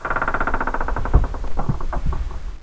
Index of /svencoop/sound/n_hq2/debris
pushbox1.wav